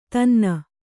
♪ tanna